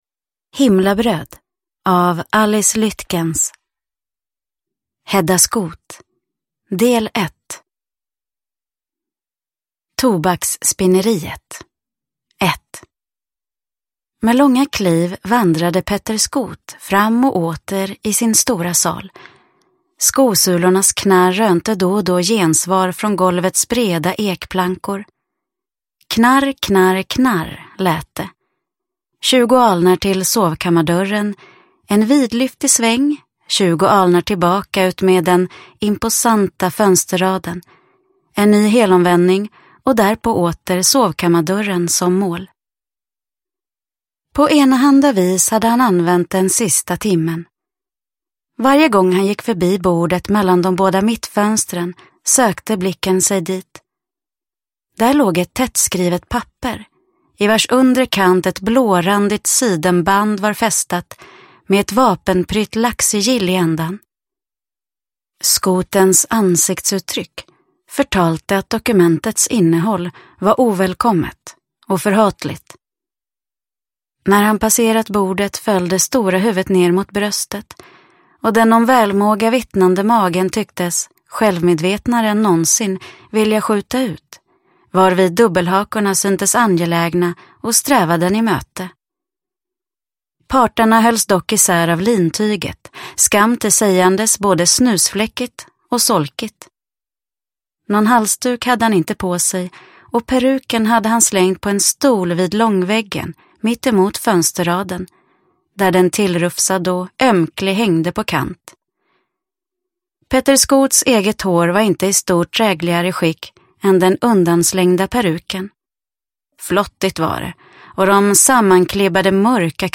Himlabröd – Ljudbok – Laddas ner